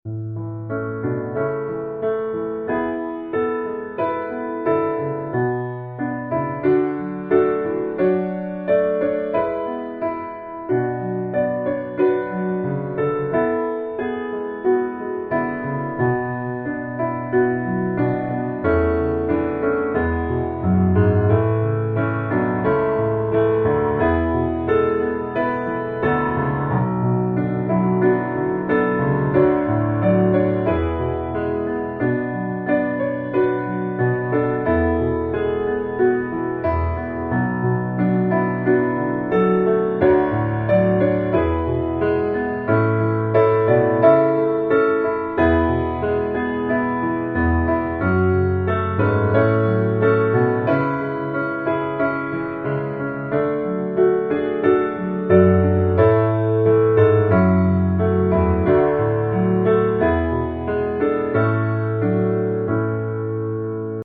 A Majeur